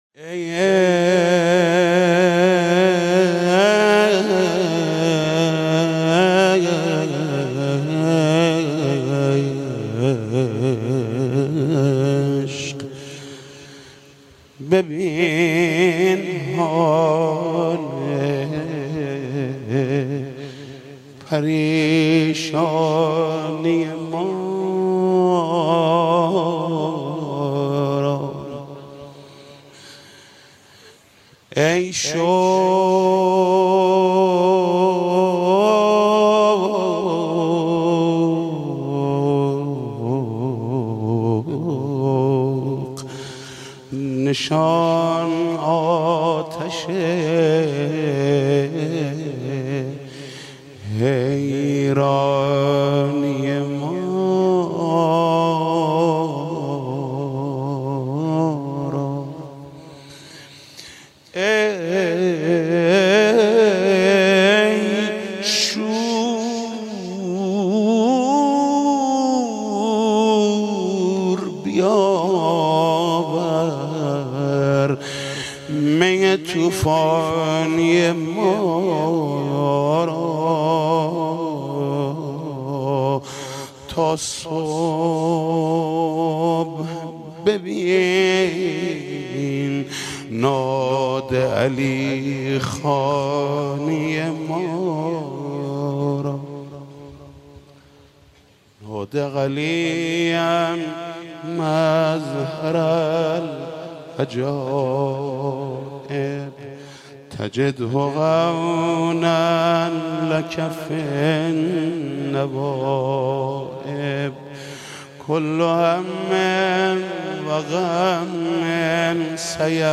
مدح: ای عشق ببین حال پریشانی ما را